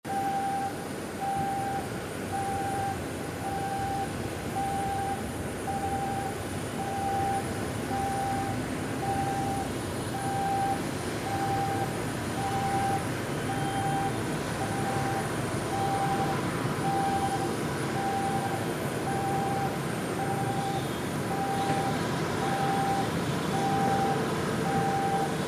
【環境音シリーズ】神戸空港│飛行機離陸音
ボーディングーブリッジ
画像加工と音声加工しました。
撮影は展望デッキのフェンスからスマホだけを乗り出させて撮影しました。
TASCAM(タスカム) DR-07Xのステレオオーディオレコーダー使用しています。